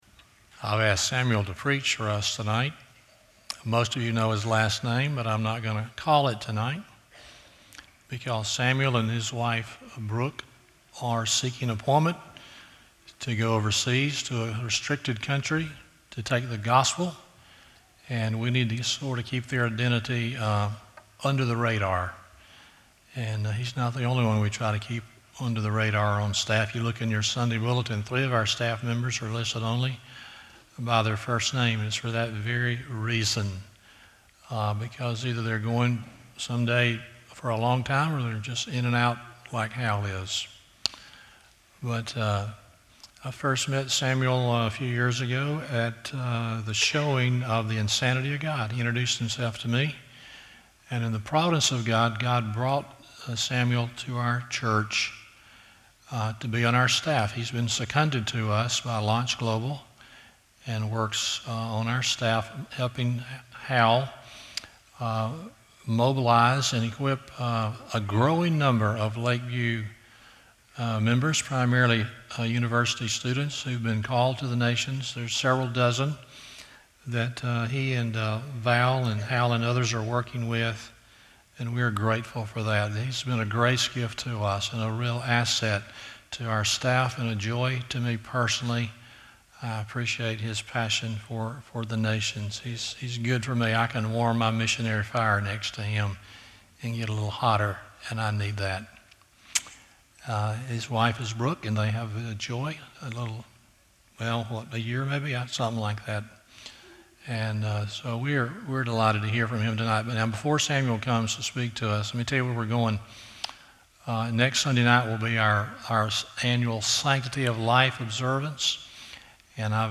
Romans 15:8-24 Service Type: Sunday Evening 1.